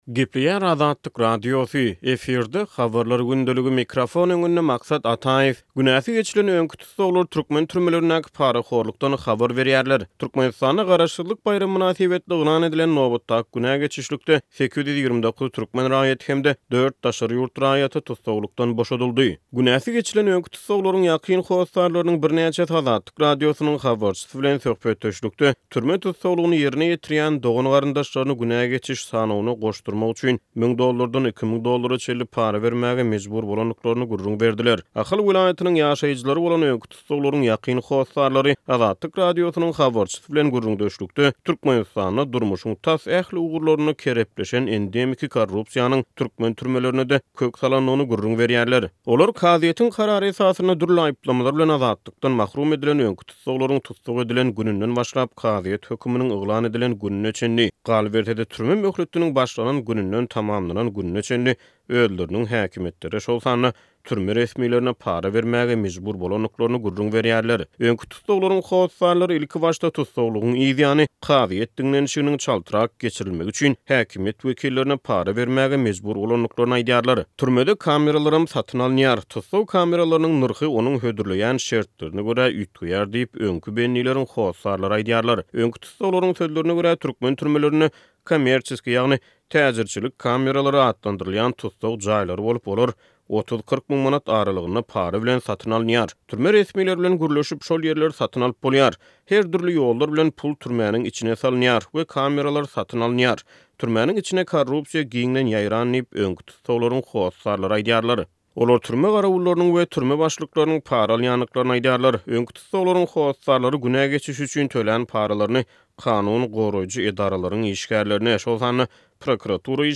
Türkmenistanda garaşsyzlyk baýramy mynasybetli yglan edilen nobatdaky günä geçişlikde 829 türkmen raýaty hem-de dört daşary ýurt raýaty tussaglykdan boşadyldy. Günäsi geçilen öňki tussaglaryň ýakyn hossarlarynyň birnäçesi Azatlyk Radiosynyň habarçysy bilen söhbetdeşlikde türme tussaglygyny ýerine ýetirýän dogan-garyndaşlaryny günä geçiş sanawyna goşdurmak üçin 1000 dollardan 2000 dollara çenli para bermäge mejbur bolandyklaryny gürrüň berdiler.